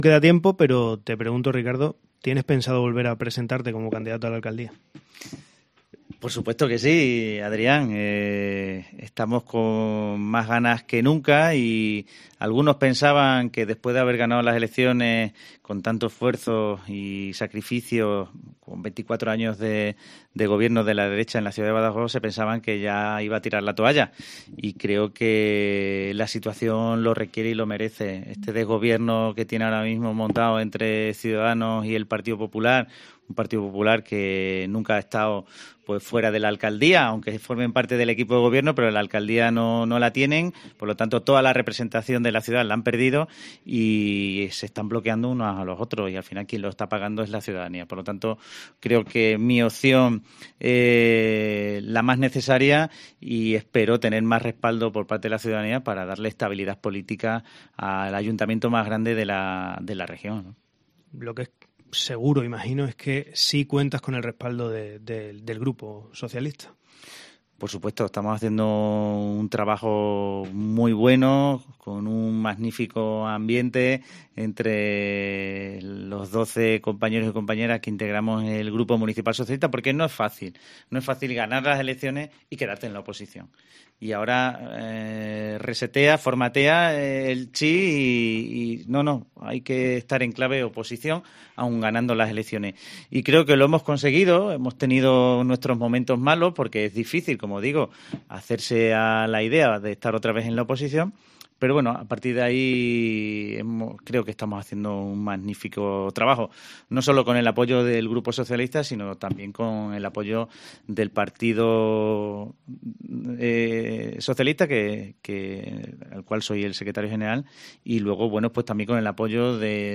ENTREVISTA RICARDO CABEZAS
El líder del Partido Socialista en Badajoz, Ricardo Cabezas, ha pasado por los micrófonos de Cope donde ha confirmado que volverá a presentarse a la alcaldía de la ciudad en 2023.